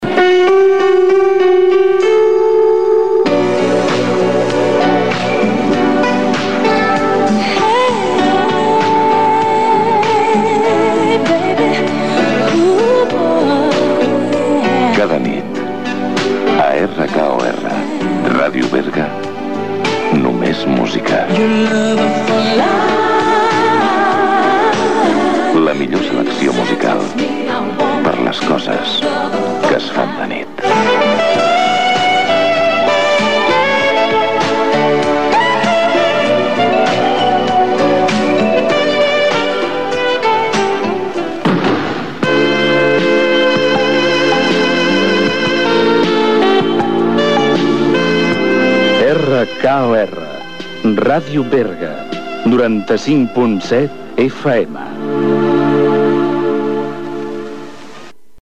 Dos indicatius de l'emissora, als 95.7 FM